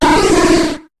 Cri de Simularbre dans Pokémon HOME.